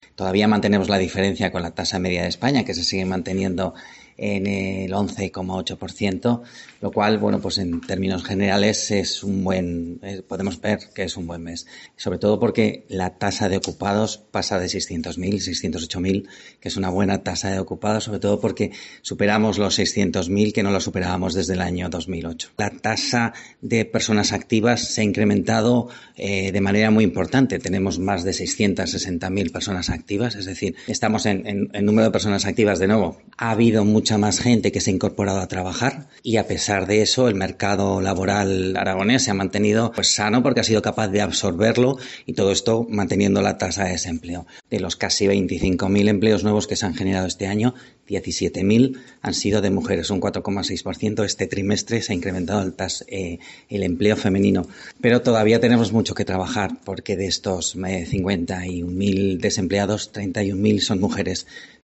Javier Martínez, director general de Política Económica, valora los datos de la EPA en Aragón